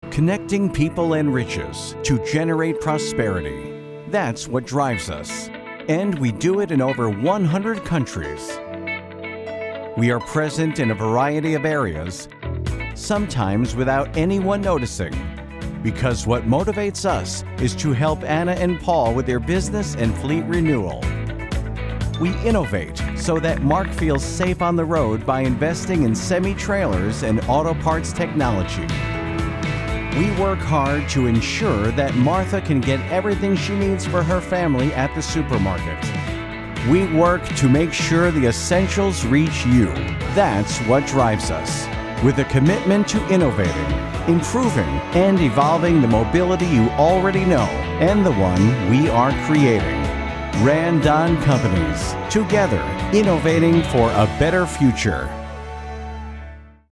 English (American)
Documentaries
My style of VO is bright, clear and articulate.
My voice can be described as clear, friendly, personable, confident, articulate, and smooth.
Sennheiser 416 mic